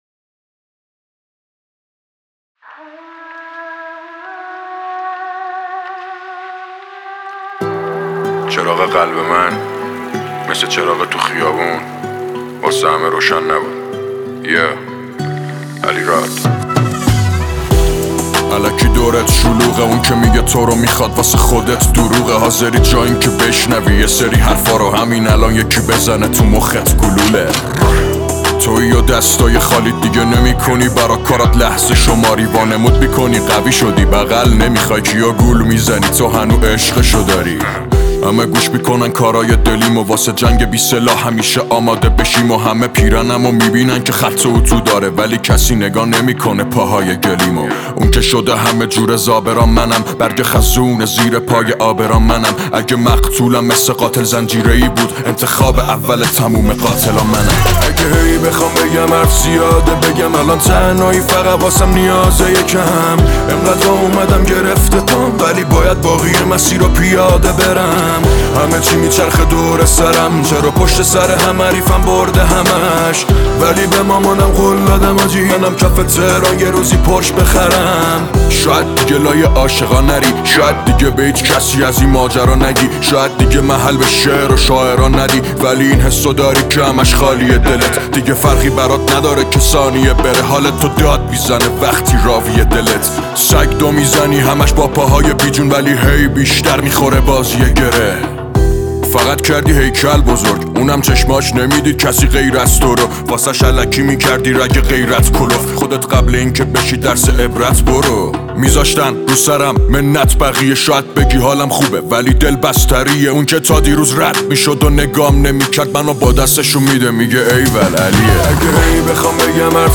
موزیک ها بر اساس سبک ها » پاپ »